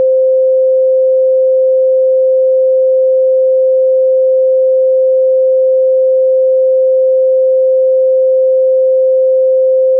※ここでは $C = 260.74Hz$ としています。
$1$ オクターブ上の $C$ の音（$C5$）
C5.wav